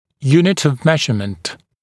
[‘juːnɪt əv ‘meʒəmənt][‘йу:нит ов ‘мэжэмэнт]единица измерения